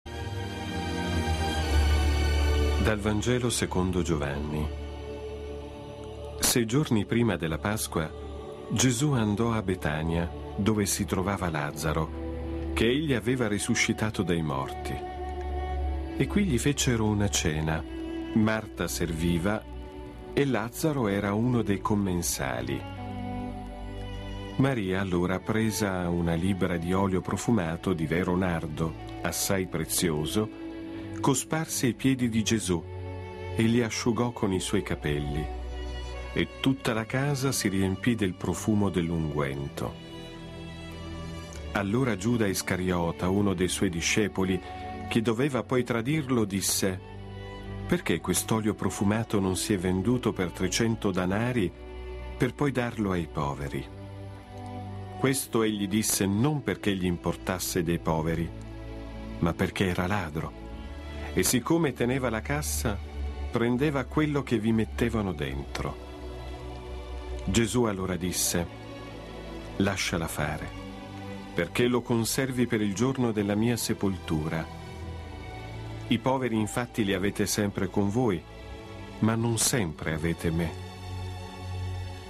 ASCOLTA LA LETTURA DI Gv 12,1-8